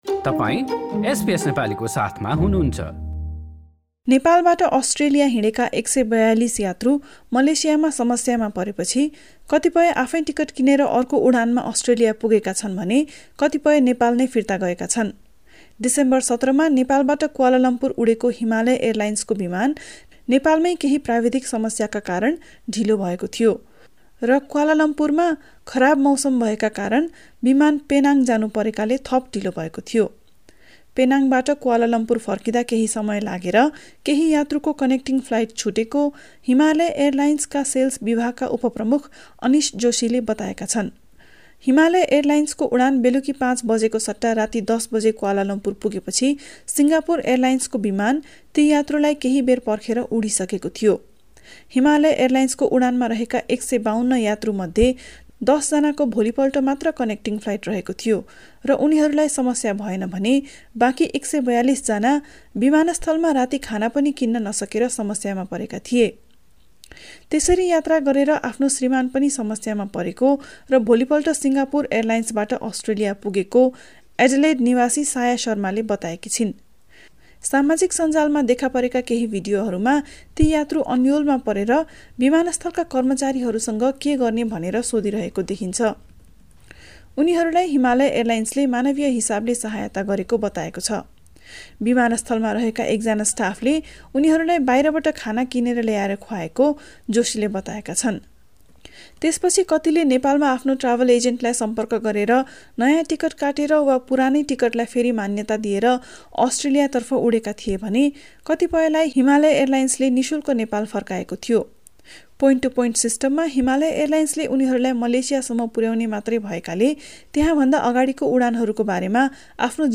nepali_2312_stringerreport_0.mp3